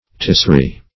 Tisri \Tis"ri\, n. [Heb. tishr[imac], fr. Chald. sher[=a]' to